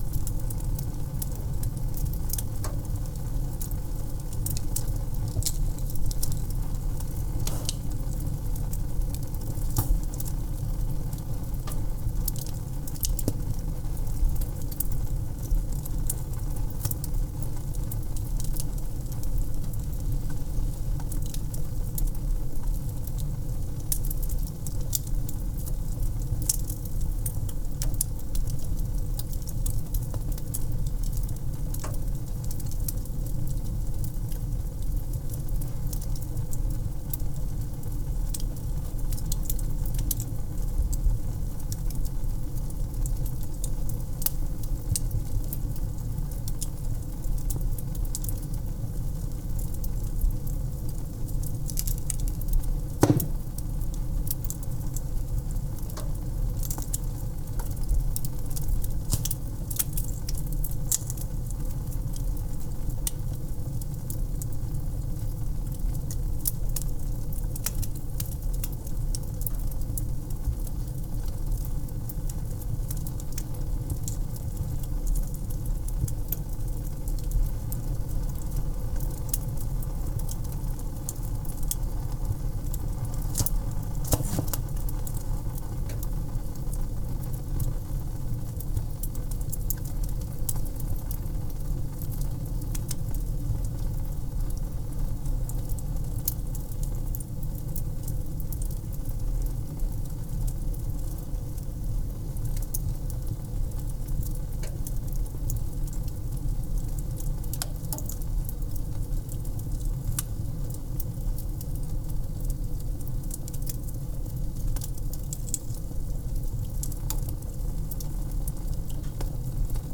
fire-2.ogg